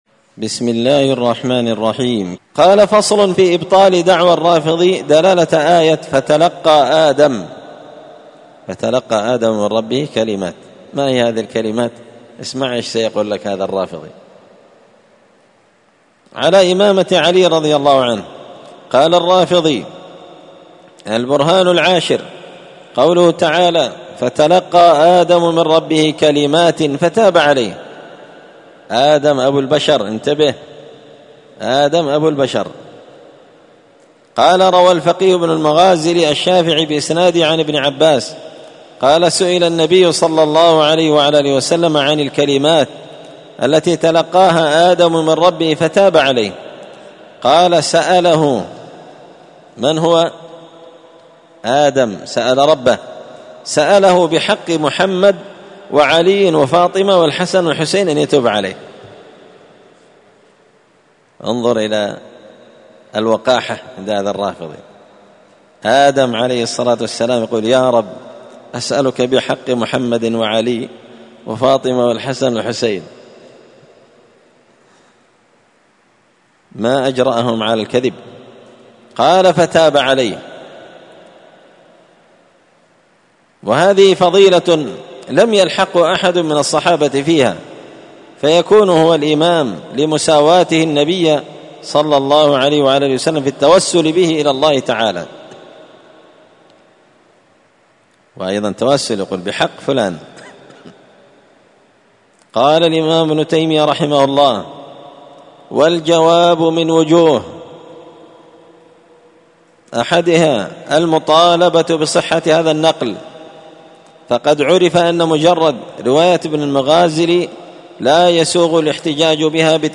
الأربعاء 29 محرم 1445 هــــ | الدروس، دروس الردود، مختصر منهاج السنة النبوية لشيخ الإسلام ابن تيمية | شارك بتعليقك | 71 المشاهدات